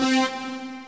synt55.wav